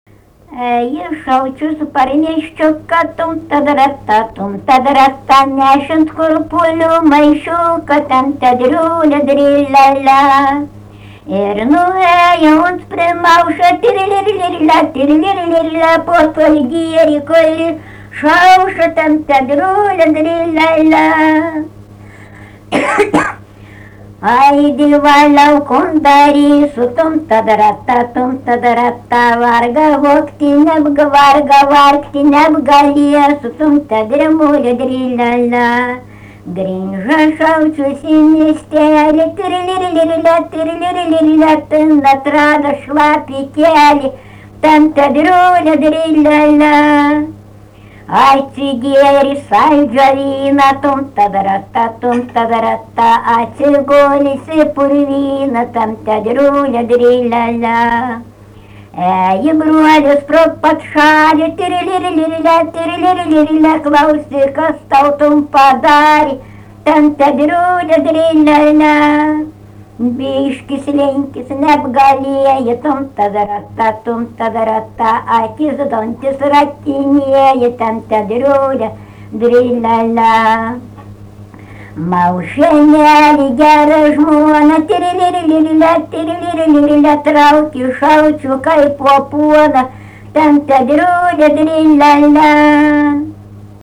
Dalykas, tema daina
Erdvinė aprėptis Barvydžiai
Atlikimo pubūdis vokalinis